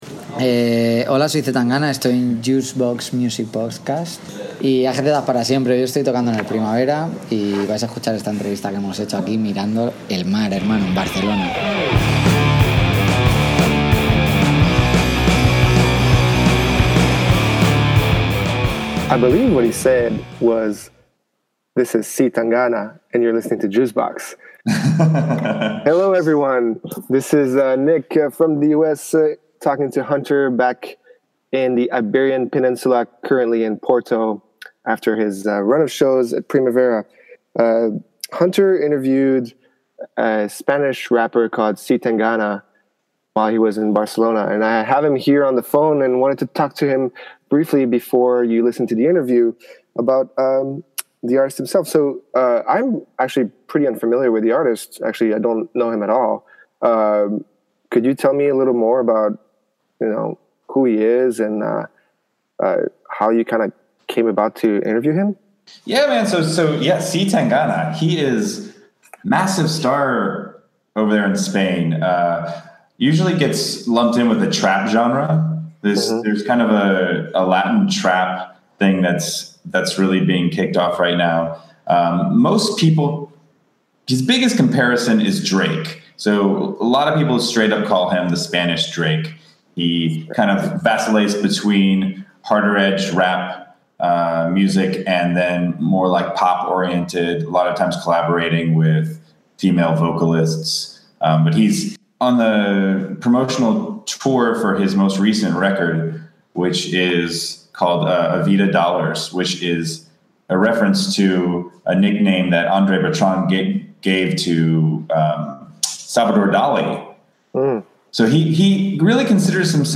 Our first international interview!